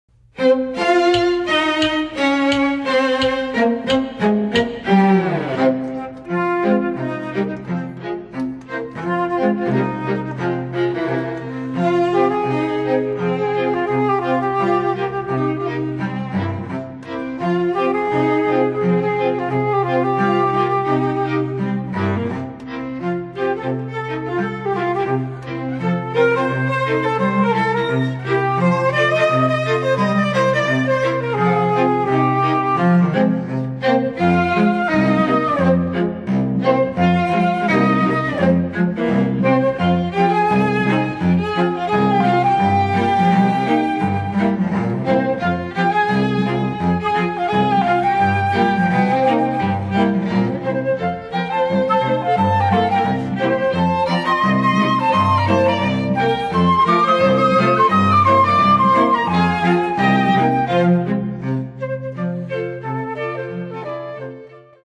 FLUTE QUARTET
(Flute, Violin, Viola and Cello)
(Two Violins, Viola and Cello)
MIDI